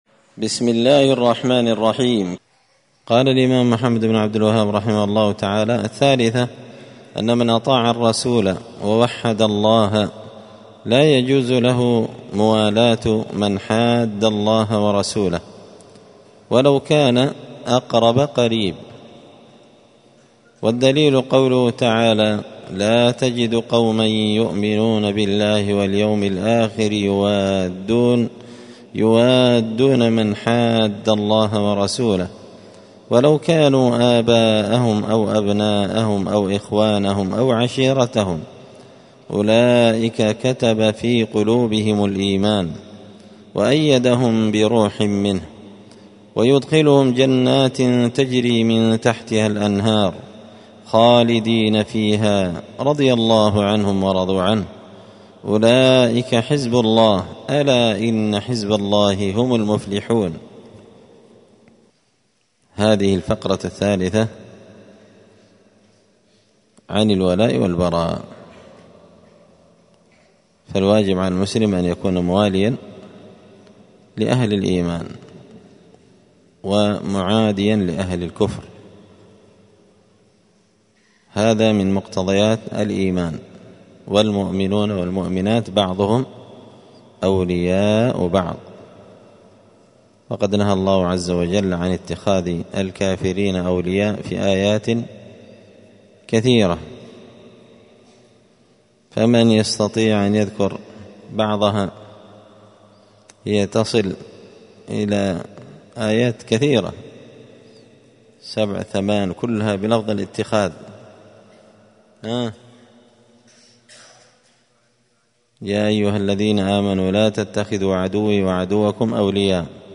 الخميس 11 ربيع الثاني 1445 هــــ | الدروس، حاشية الأصول الثلاثة لابن قاسم الحنبلي، دروس التوحيد و العقيدة | شارك بتعليقك | 28 المشاهدات
مسجد الفرقان قشن_المهرة_اليمن